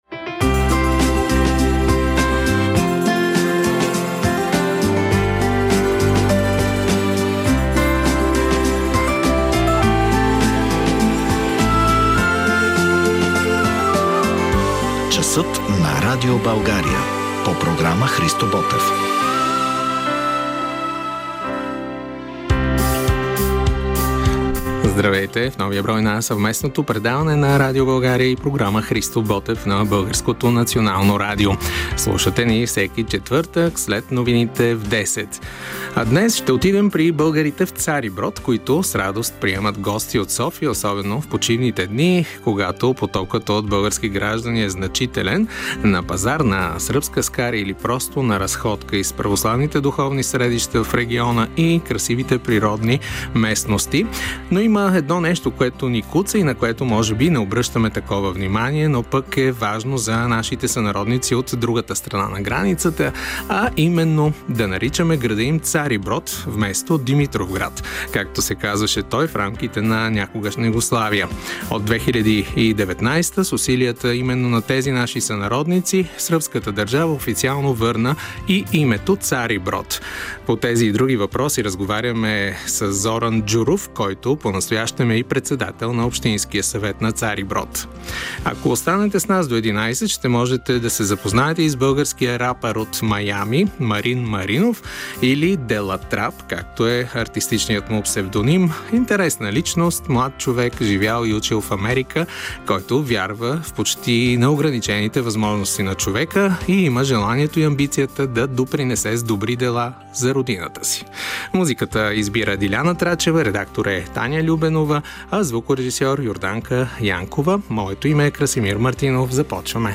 Разговор с председателя на общинския съвет на Цариброд Зоран Джуров